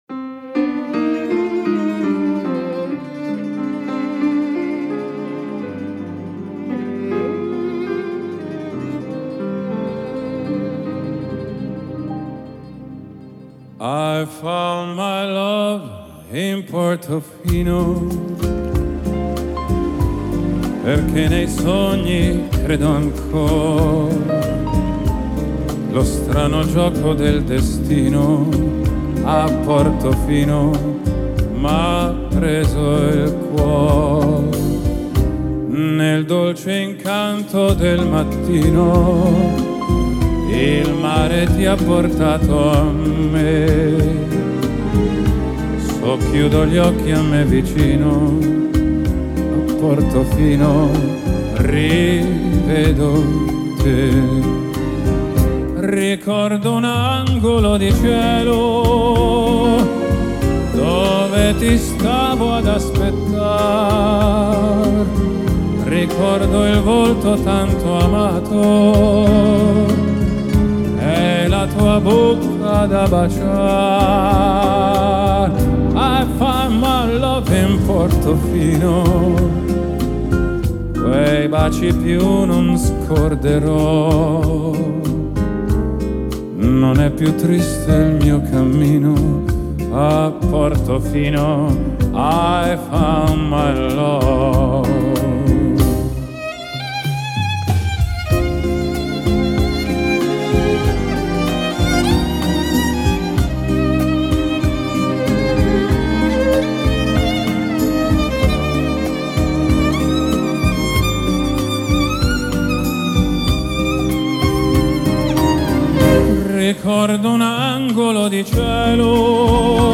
Genre: Vocal, Classical, Crossover